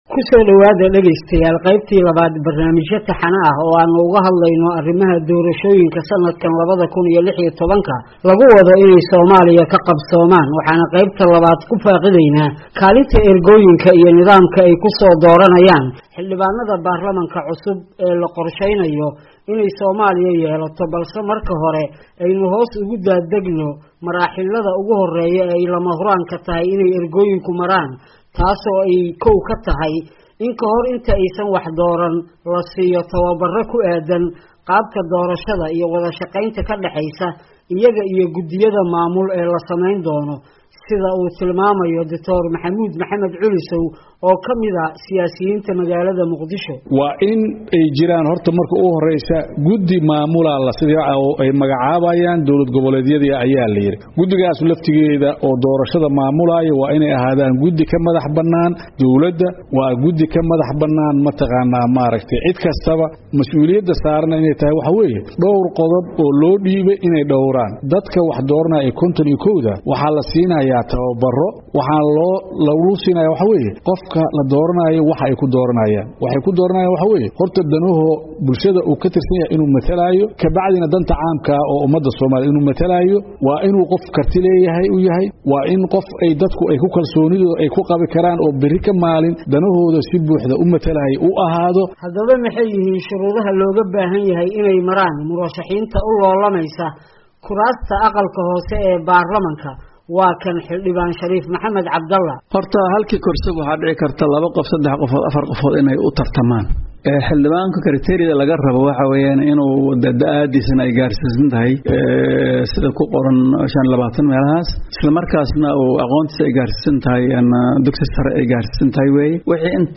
Warbixin gaar ah: Habka Soo Xulista Ergooyinka Doorashada